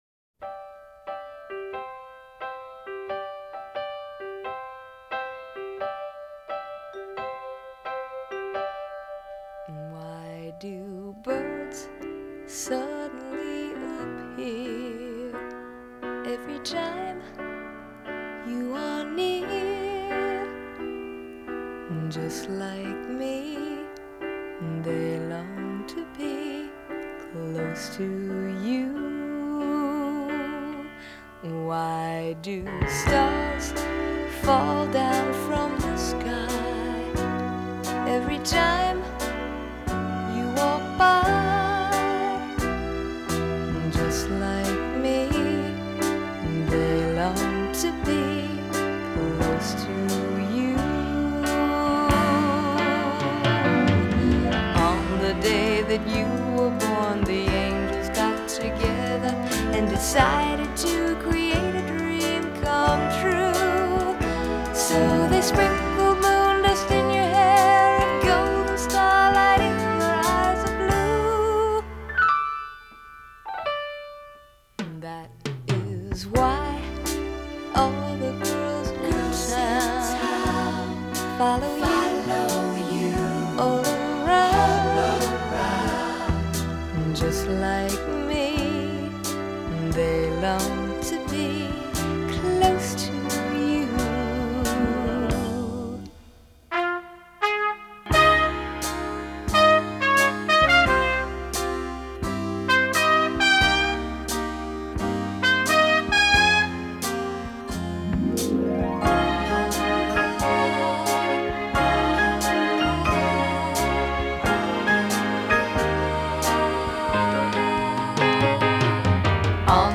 This is the original stereo single version.